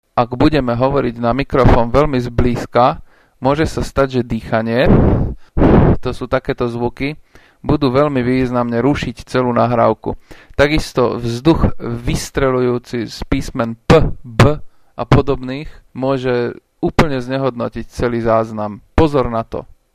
mikrofon_plozivy.mp3